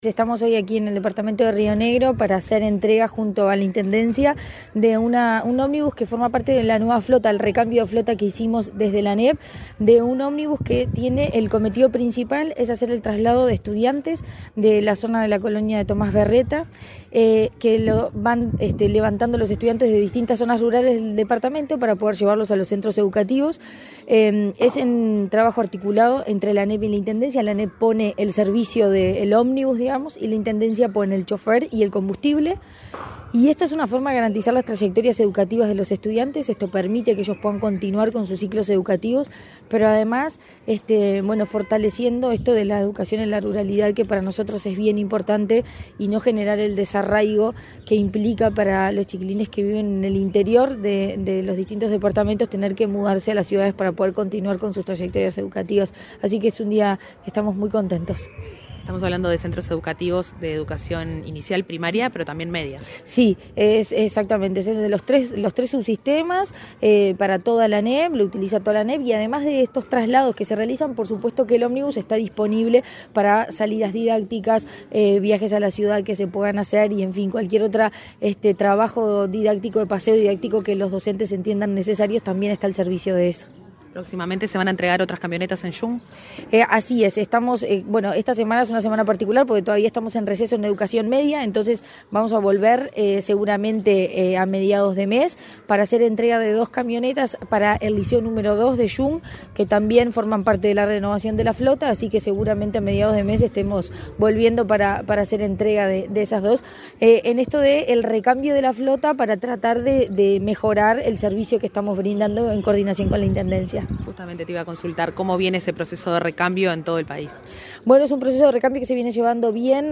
Entrevista a la presidenta de la ANEP, Virginia Cáceres
La presidenta de la Administración Nacional de Educación Pública (ANEP), Virginia Cáceres, dialogó con Comunicación Presidencial en Río Negro, antes